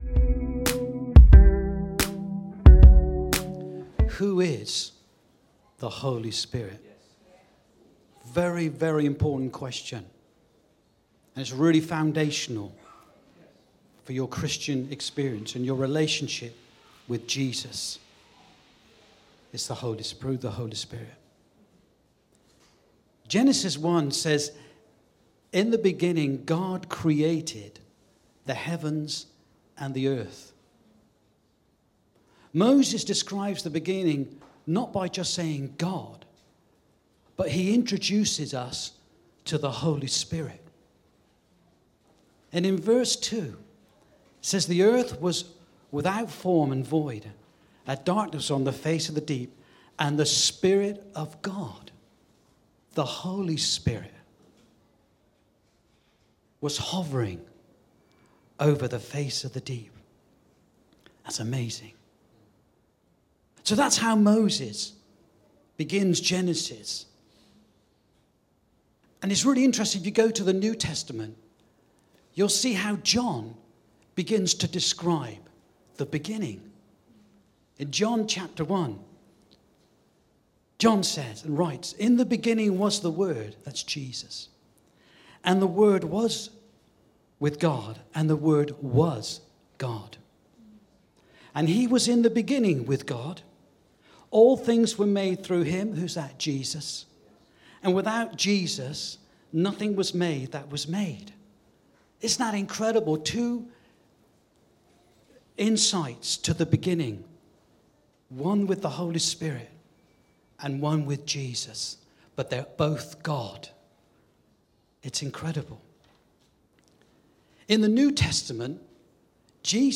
Rediscover Church Newton Abbot | Sunday Messages Who is the Holy Spirit?